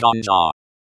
espeak_ganzha.ogg